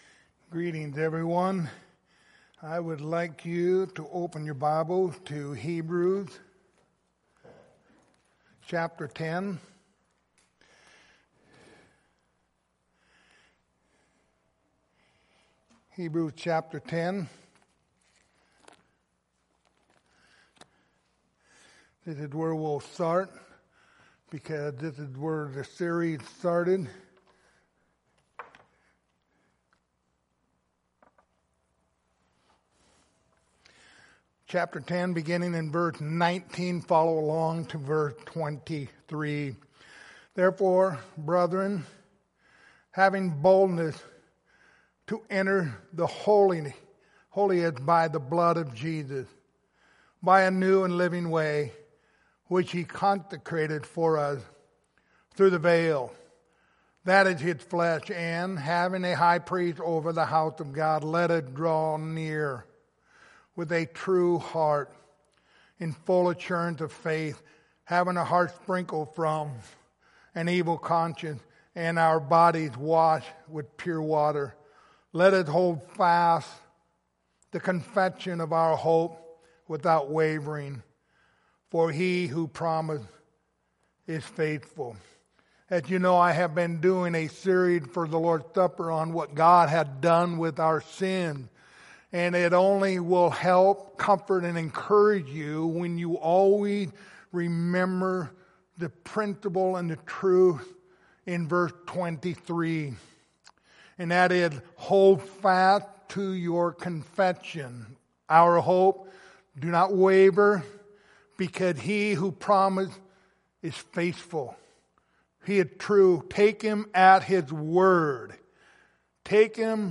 Service Type: Lord's Supper